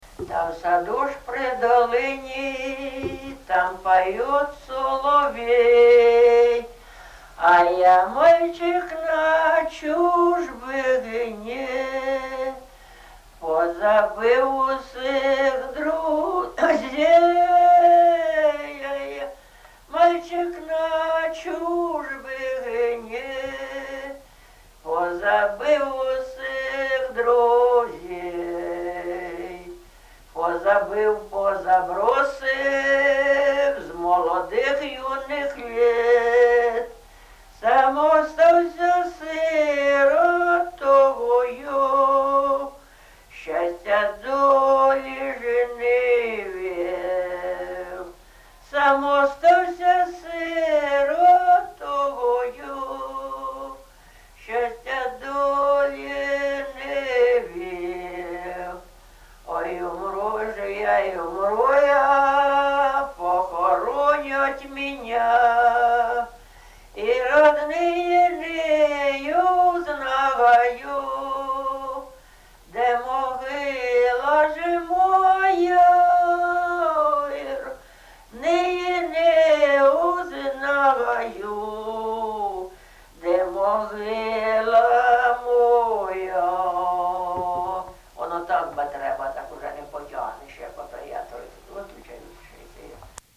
ЖанрПісні з особистого та родинного життя
Місце записус. Софіївка, Краматорський район, Донецька обл., Україна, Слобожанщина